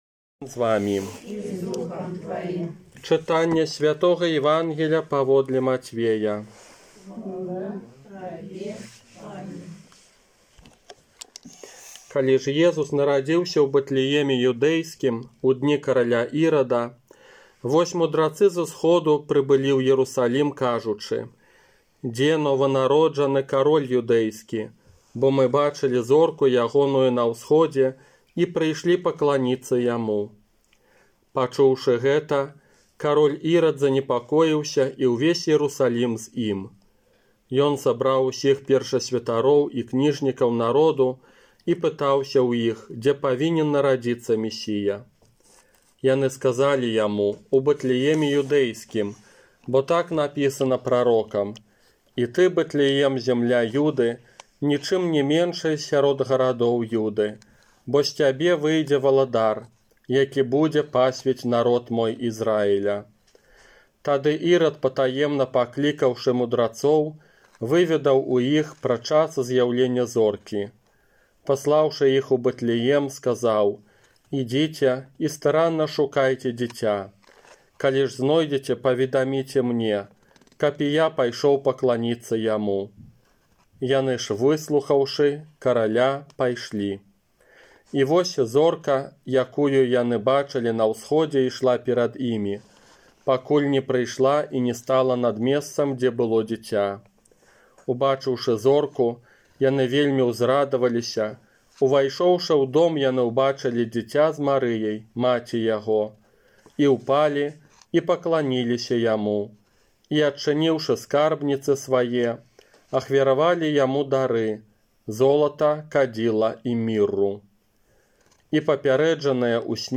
ОРША - ПАРАФІЯ СВЯТОГА ЯЗЭПА
Казанне на Ўрачыстасць Аб'яўлення Пана